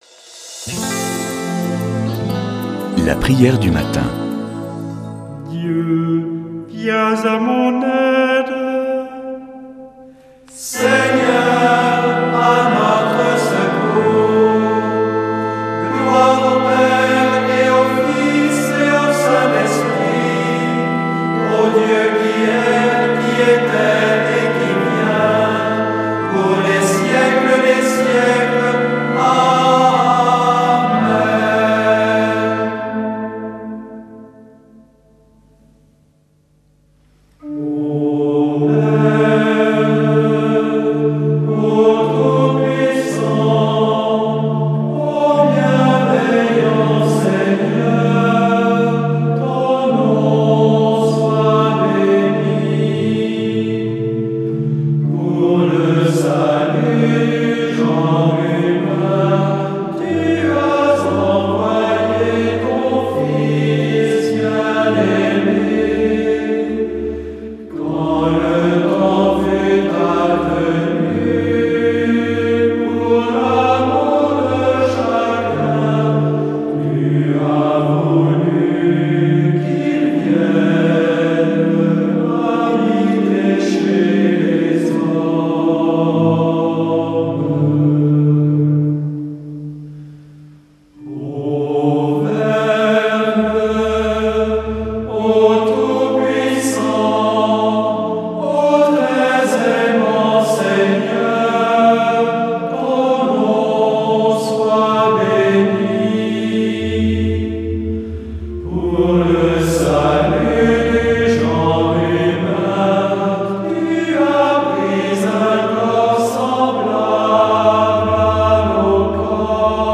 Prière du matin
ABBAYE DE TAMIE